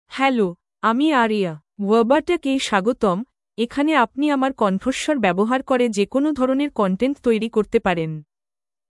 Aria — Female Bengali (India) AI Voice | TTS, Voice Cloning & Video | Verbatik AI
Aria is a female AI voice for Bengali (India).
Voice sample
Female
Aria delivers clear pronunciation with authentic India Bengali intonation, making your content sound professionally produced.